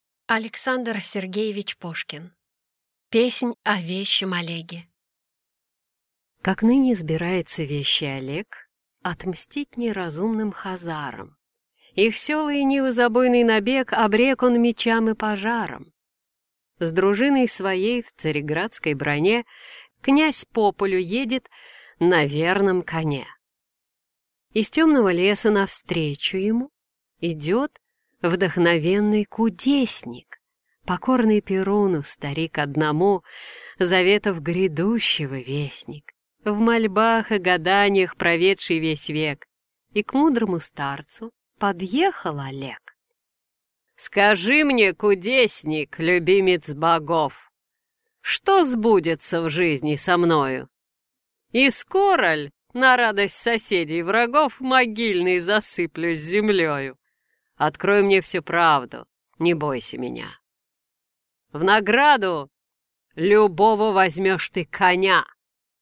Аудиокнига Песнь о вещем Олеге | Библиотека аудиокниг